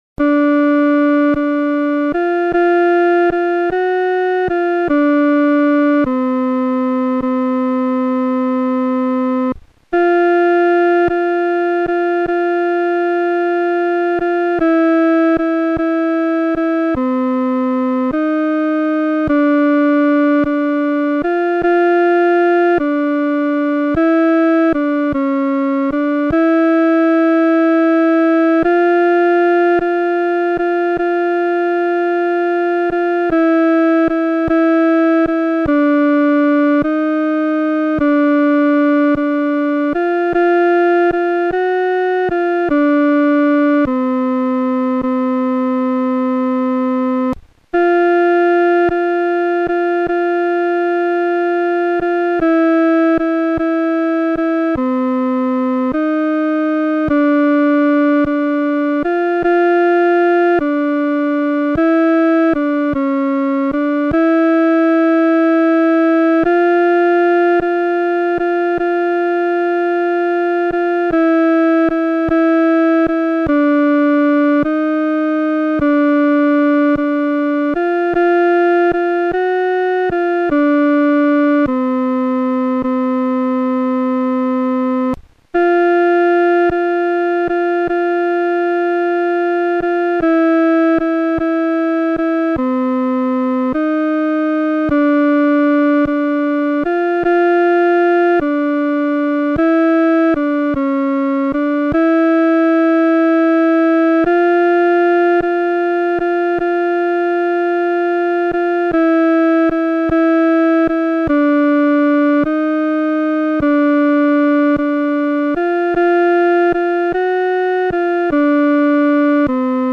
伴奏
女低
这首圣诗速度不宜快，要预备敬虔、认罪的心来弹唱。